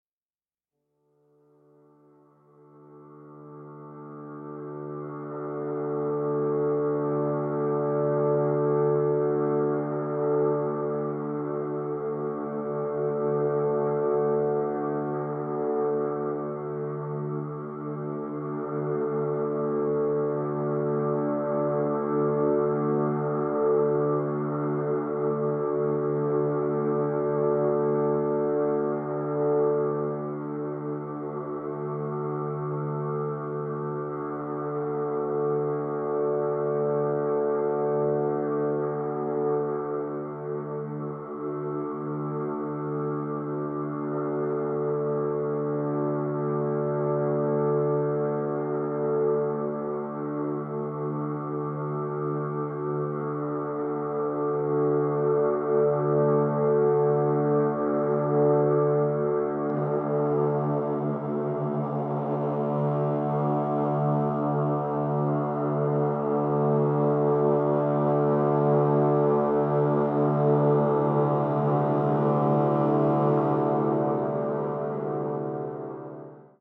for trombone and electronics